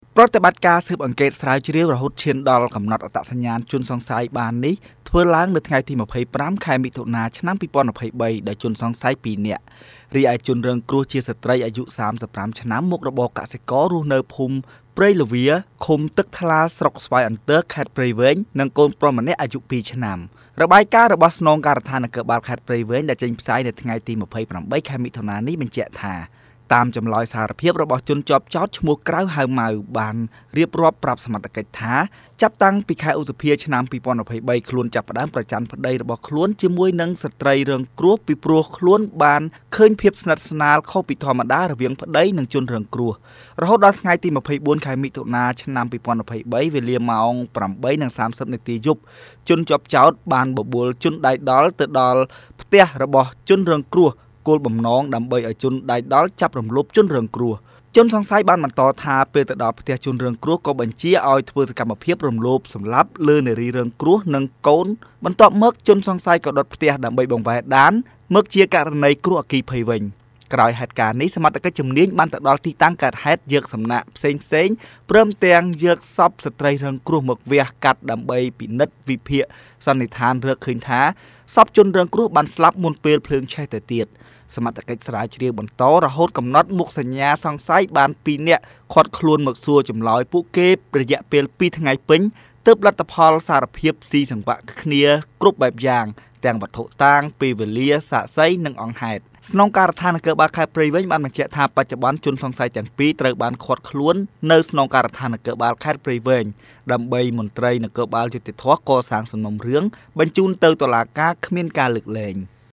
រាយការណ៍